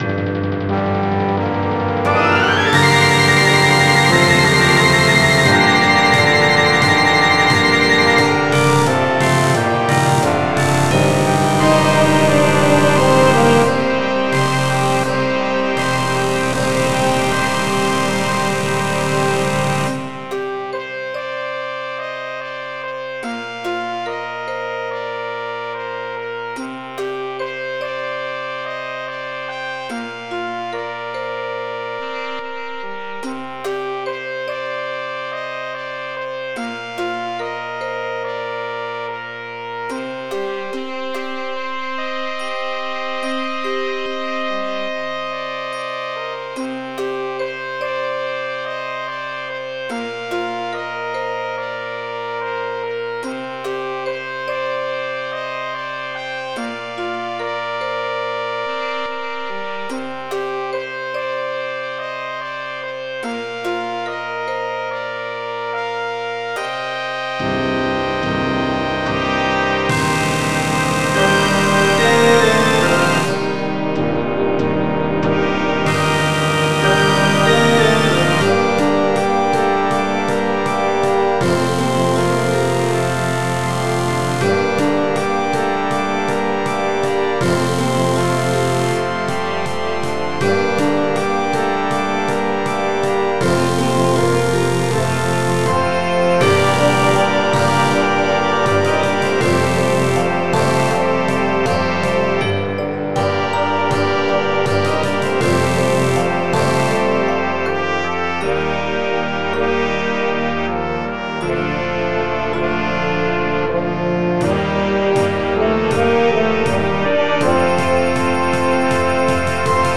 A nice challenge for a high school band.